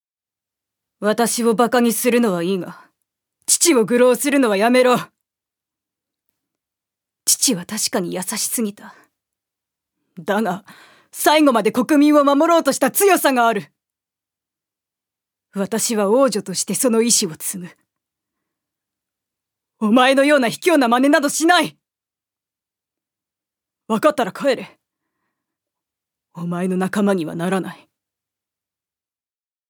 ジュニア：女性
セリフ４